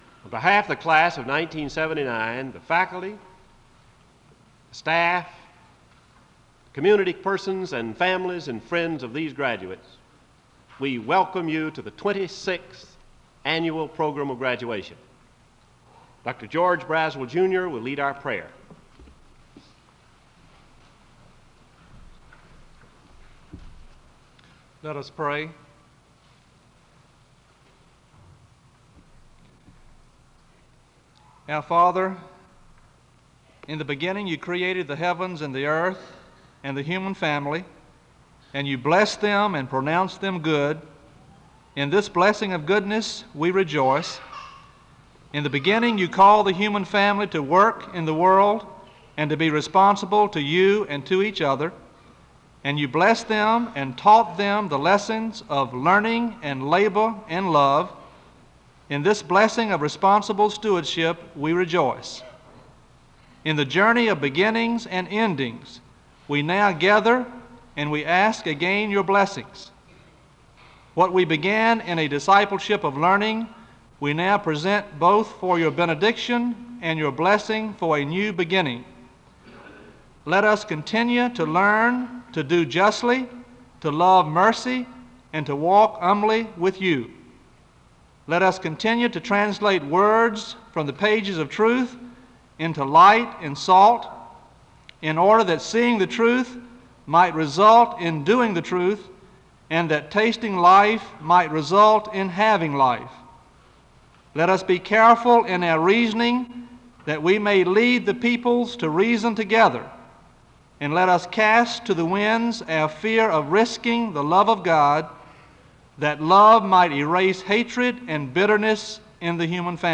SEBTS Commencement - May 12, 1979